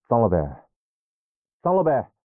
三楼/囚室/肉铺配音偷听效果处理；